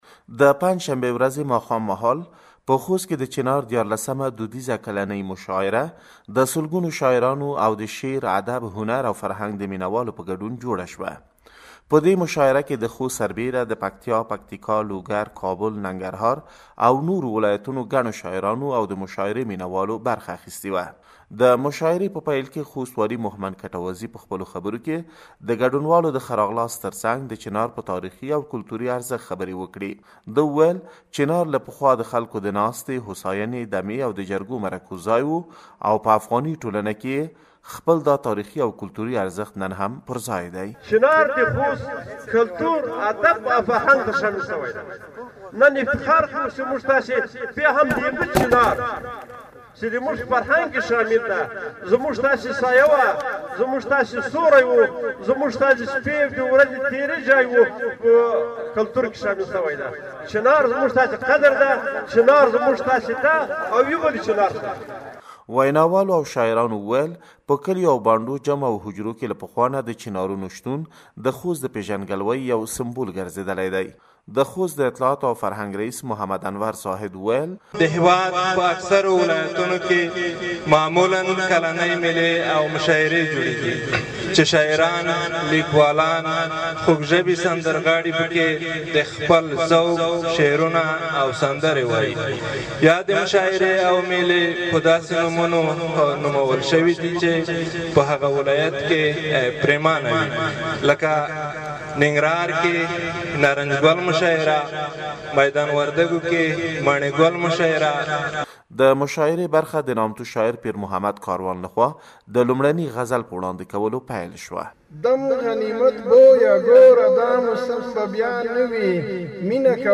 په خوست کې د چنار دودیزه کلنۍ مشاعره
د افغانستان په خوست کې د چنار په نامه دودیزه کلنۍ مشاعره کې د خوست تر څنګ د یو شمېر نورو ولایتونو فرهنګیانو او شاعرانو هم برخه اخیستې وه.